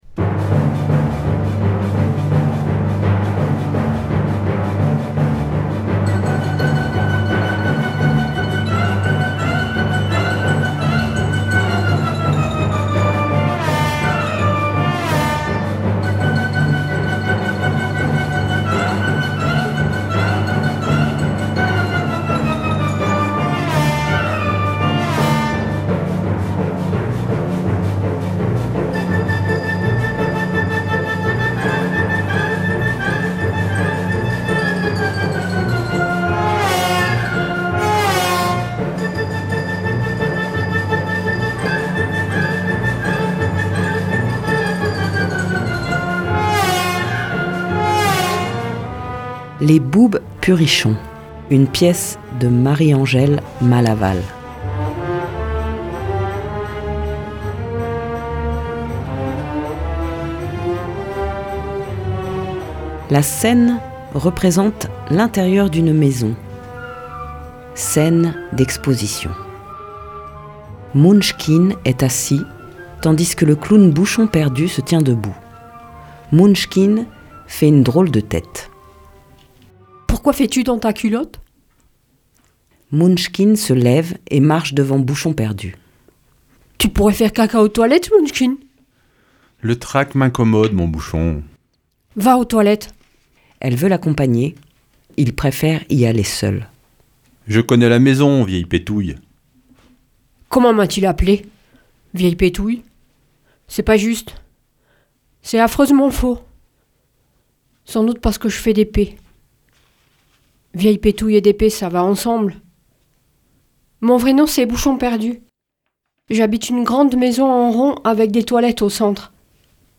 Pièce radiophonique : les Boubs purichons & Gratouille - Radio Larzac